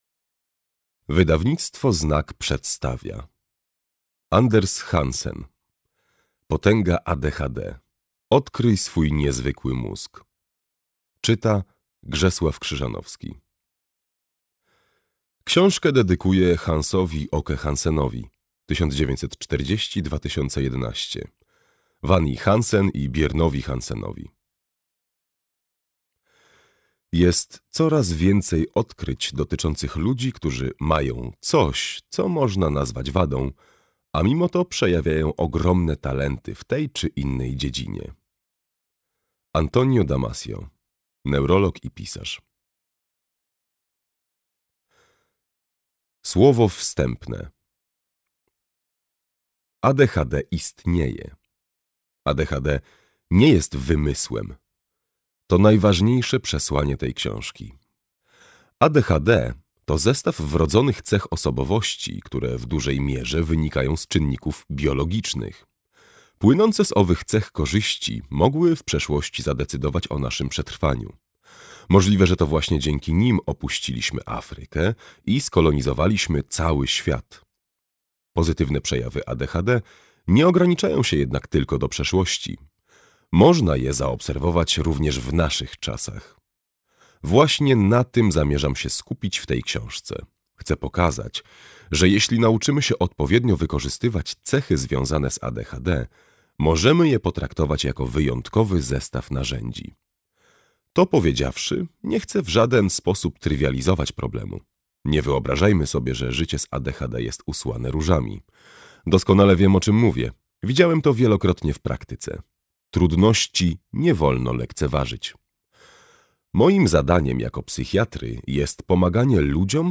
Potęga ADHD - Hansen Andres - audiobook